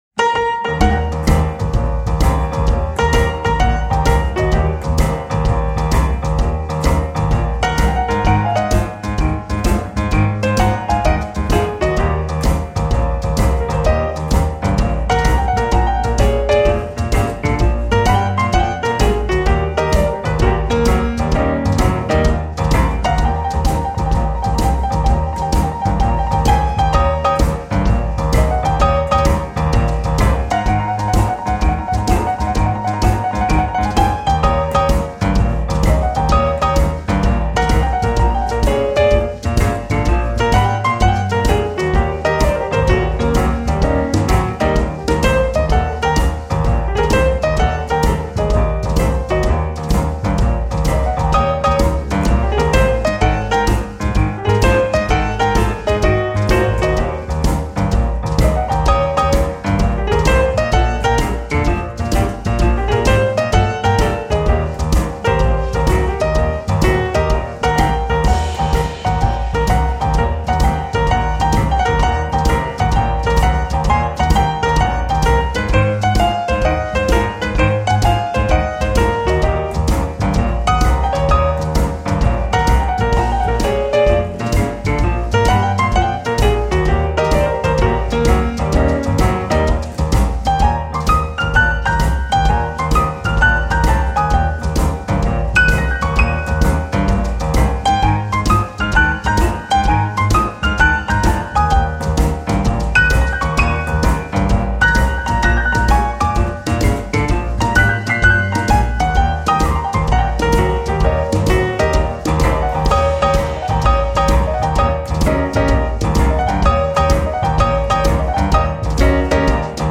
• Includes pure unadulterated boogie woogie and blues.
• I play on a Steinway and Sons "B" grand piano
p/d/b